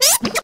quaxly_ambient.ogg